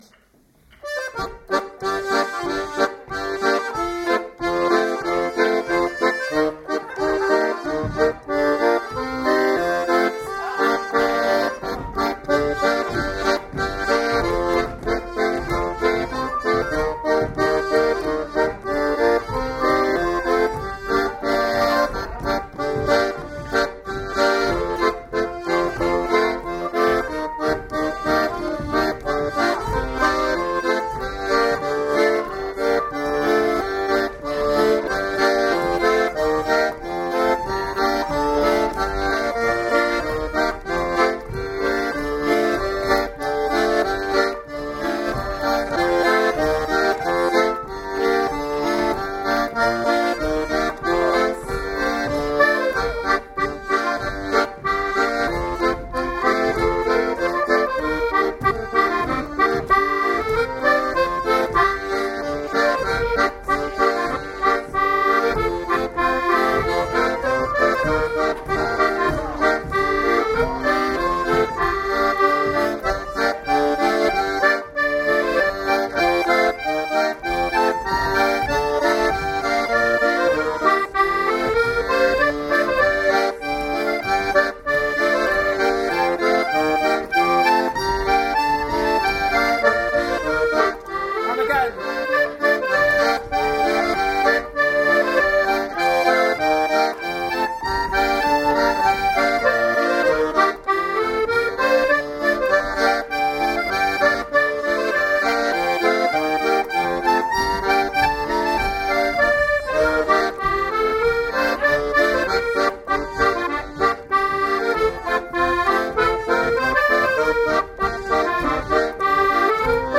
North-West Trethowan The Quarry Cross,